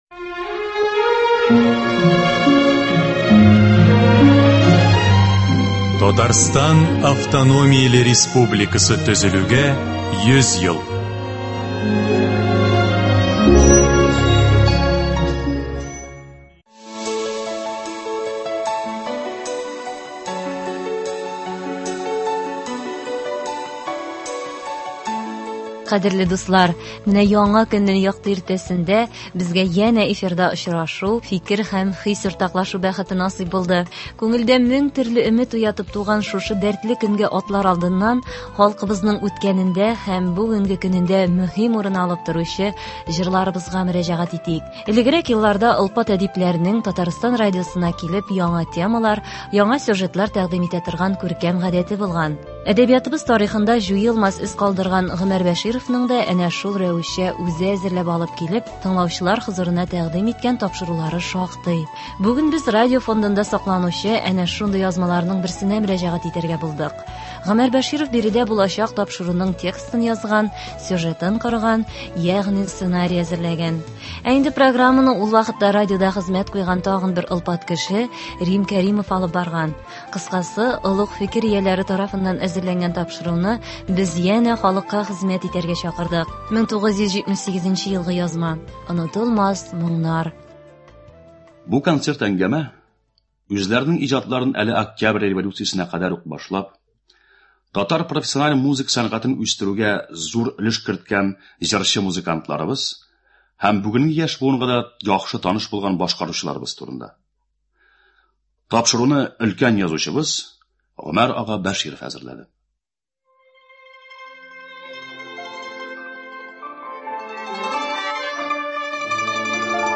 Тыңлаучылар игътибарына Татарстан радиосы фондында кадерләп сакланучы бер язма тәкъдим ителә.
Әдәби-музыкаль композиция. 6 июнь.